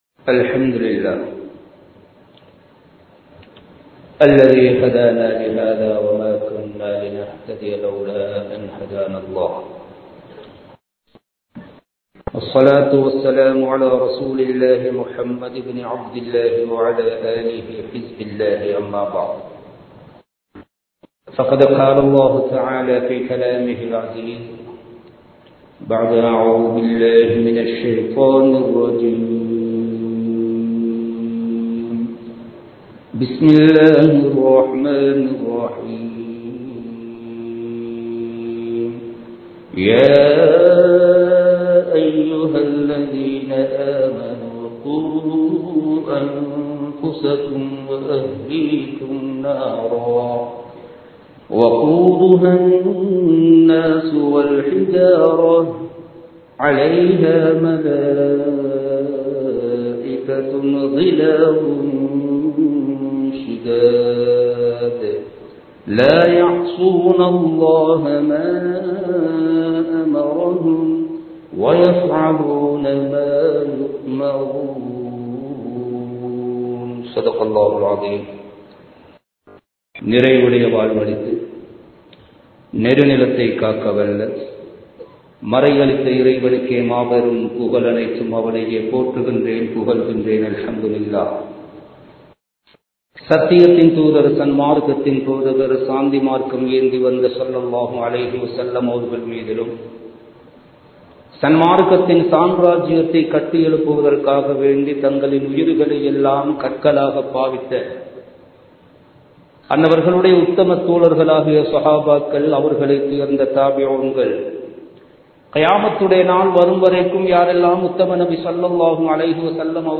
குழந்தை வளர்ப்பு | Audio Bayans | All Ceylon Muslim Youth Community | Addalaichenai
Kandy, Kattukela Jumua Masjith